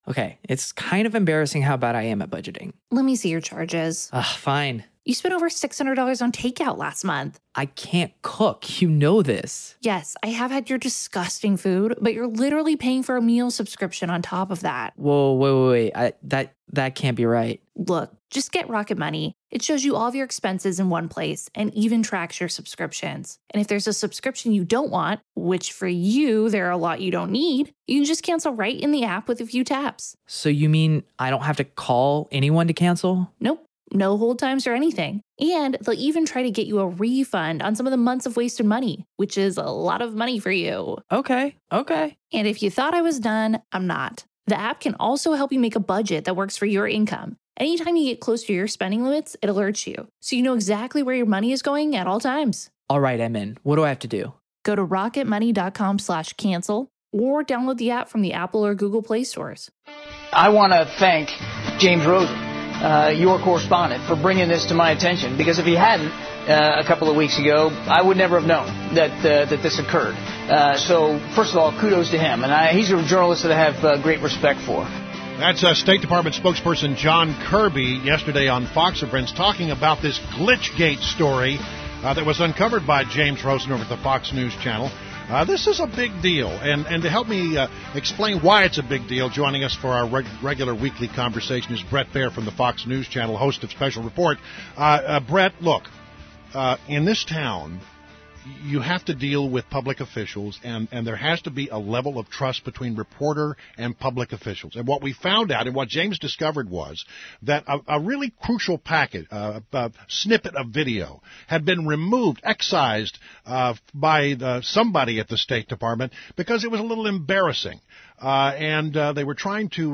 WMAL Interview - BRET BAIER - 06.03.16
INTERVIEW — BRET BAIER – Anchor, Special Report, Fox News Channel